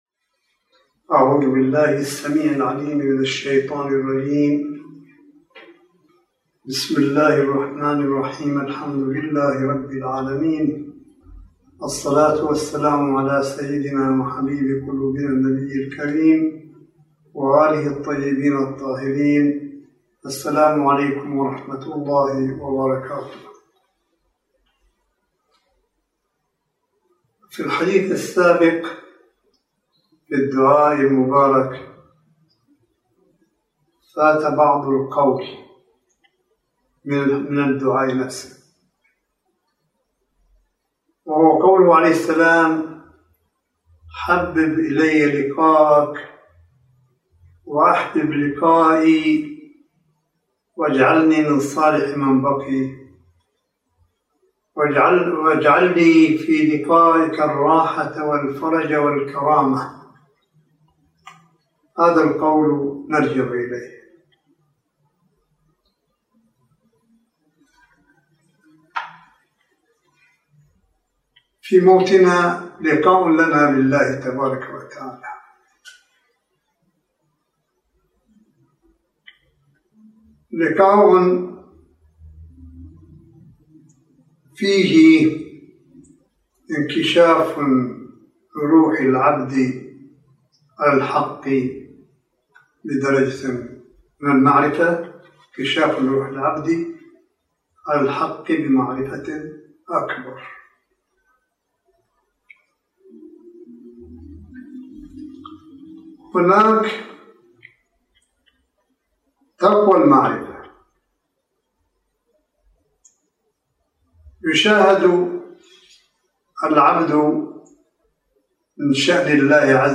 ملف صوتي للحديث الرمضاني (23) لسماحة آية الله الشيخ عيسى أحمد قاسم حفظه الله – 24 شهر رمضان 1442 هـ / 06 مايو 2021م